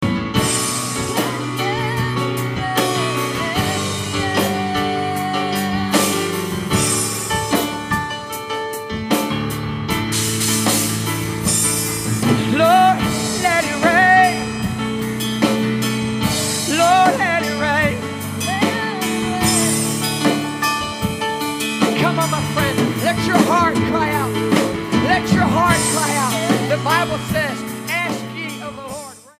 STYLE: MOR / Soft Pop
piano-backed powerful and uplifting prayer ministry